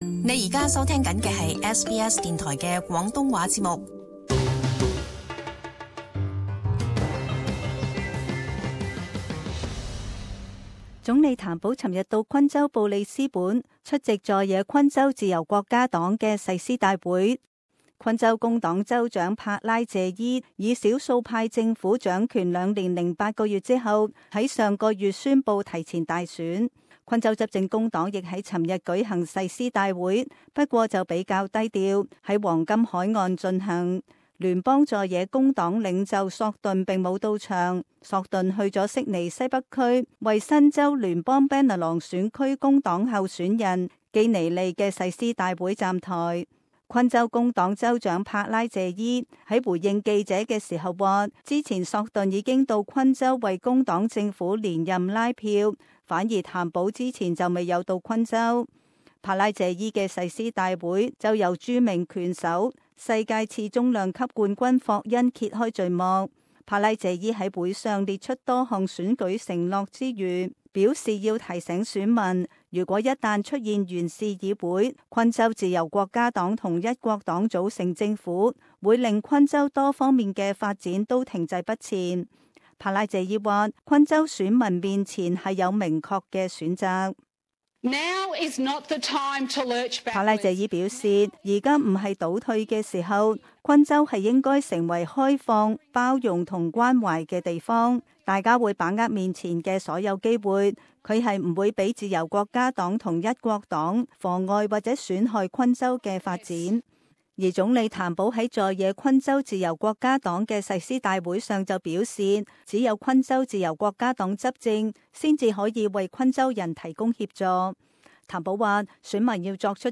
【時事報道】: 昆州大選前瞻